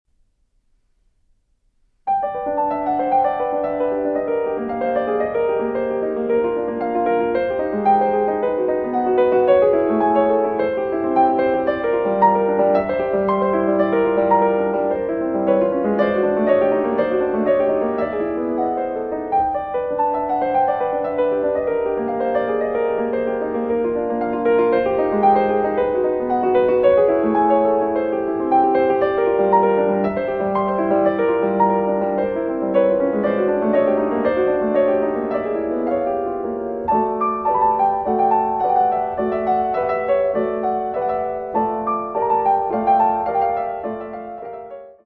Allegro (non troppo) amabile